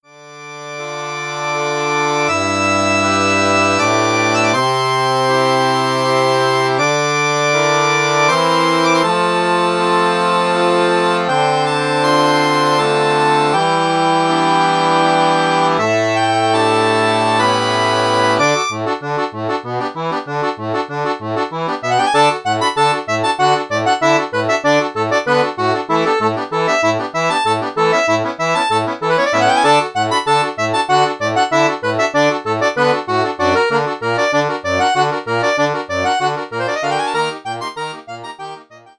accordion duet